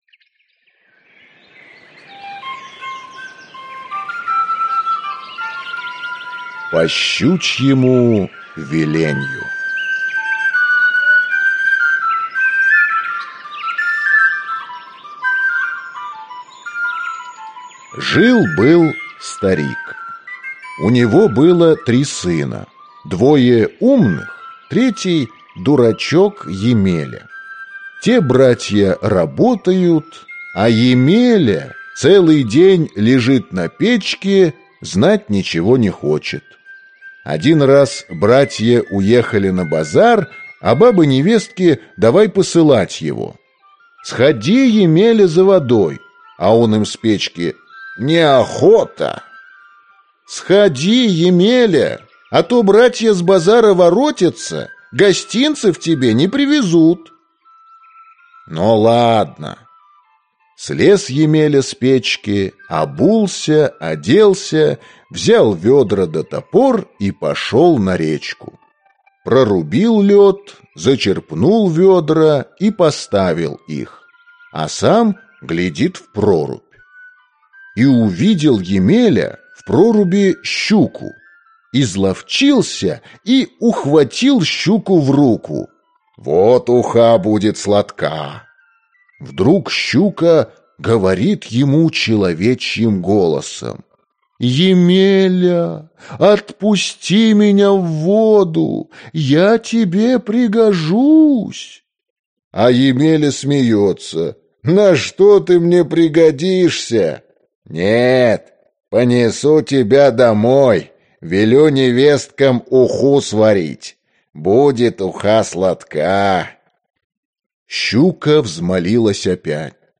Аудиокнига По щучьему велению и другие сказки | Библиотека аудиокниг